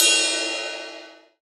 Original creative-commons licensed sounds for DJ's and music producers, recorded with high quality studio microphones.
Loudest frequency: 5577Hz Trap Crash Sound Clip F Key 24.wav .WAV .MP3 .OGG 0:00 / 0:01 Royality free crash tuned to the F note. Loudest frequency: 5912Hz
trap-crash-sound-clip-f-key-24-4Vi.wav